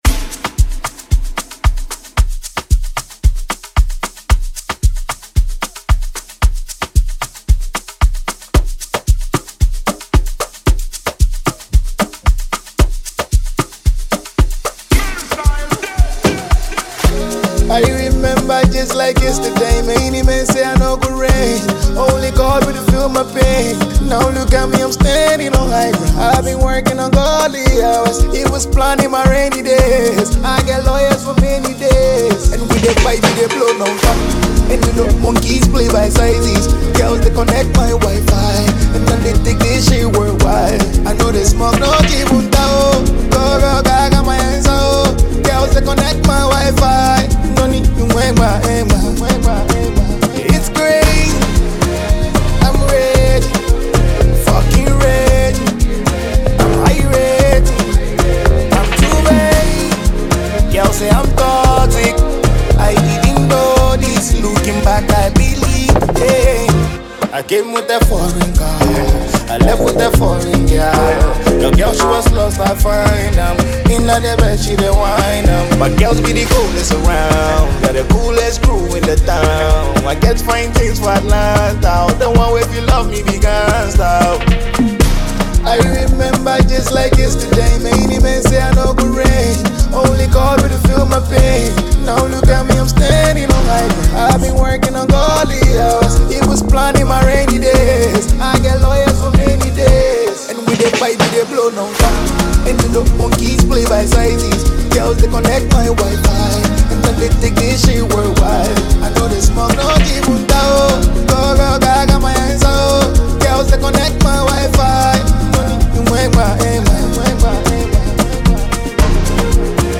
a free mp3 download with an amapiano vibe.